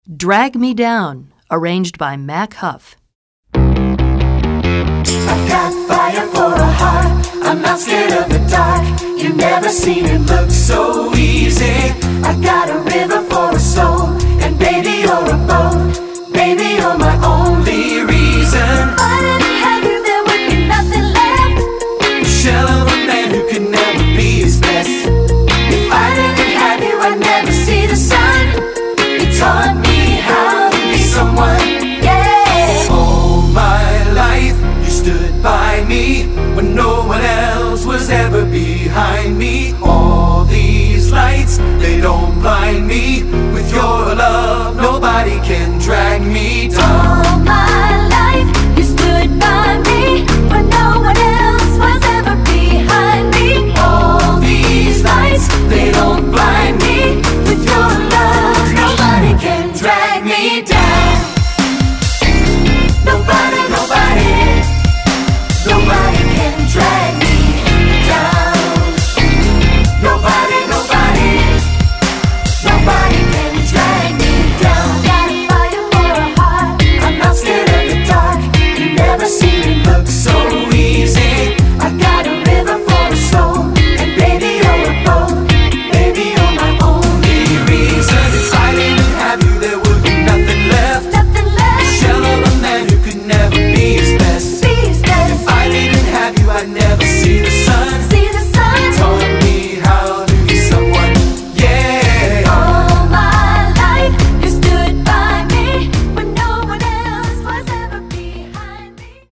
Choral Early 2000's Pop